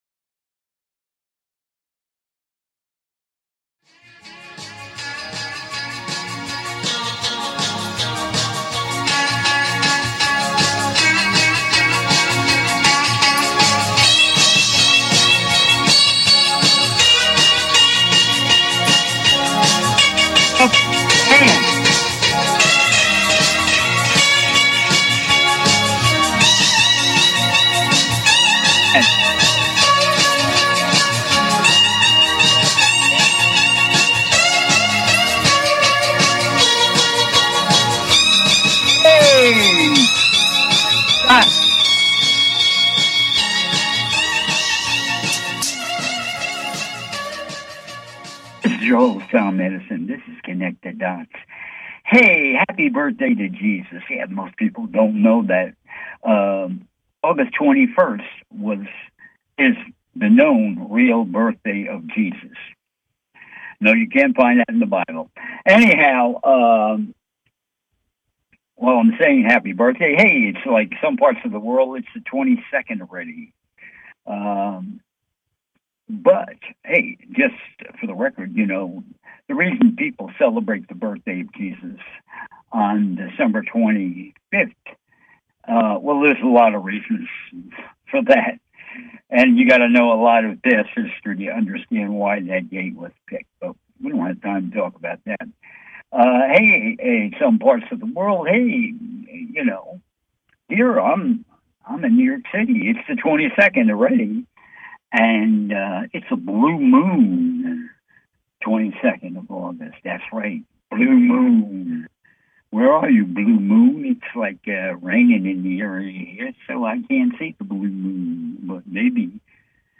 Talk Show Episode
"CONNECT THE DOTS" is a call in radio talk show, where I share my knowledge of the metaphysical, plus ongoing conspiracies, plus the evolution of planet earth - spiritual info - et involvement - politics - crystals - etc.